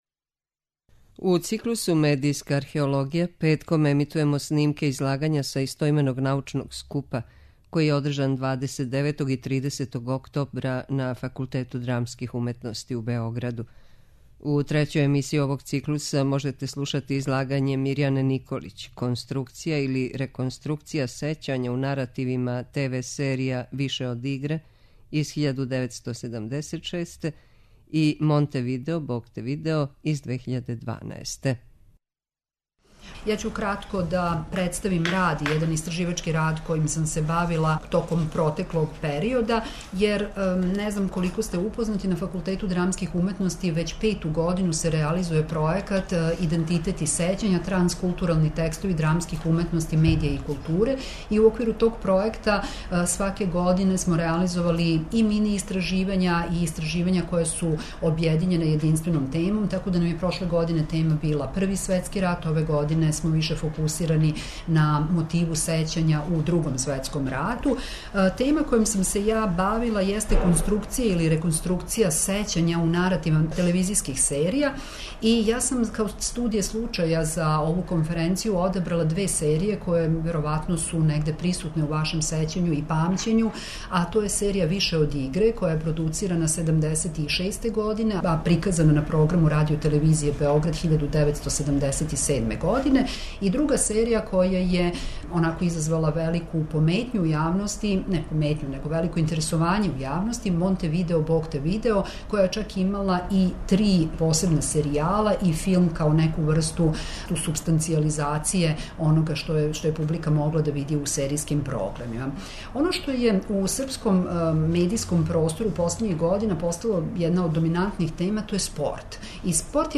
У циклусу МЕДИЈСКА АРХЕОЛОГИЈА петком ћемо емитовати снимке са истоименог научног скупа који је одржан 29. и 30. октобра на Факултету драмских уметности у Београду.